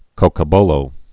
(kōkə-bōlō)